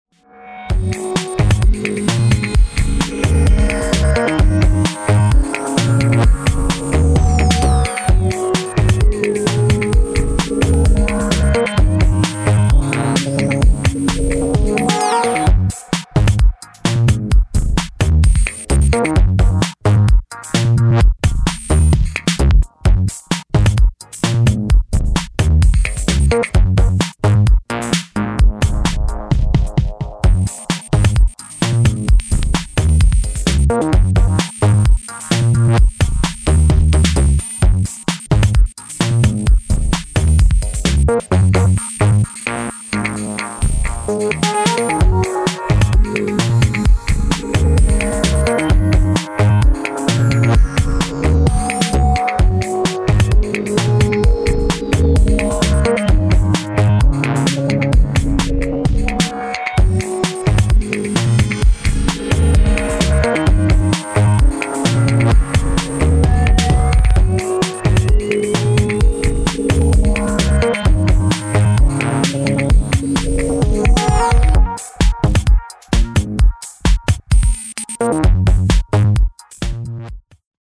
yet broodingly minimal techno tracks
Techno Sale